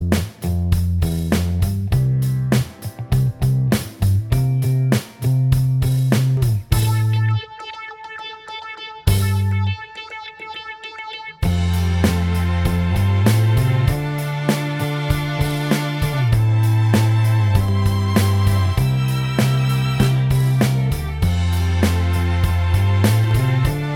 Minus Guitars Pop (1980s) 4:15 Buy £1.50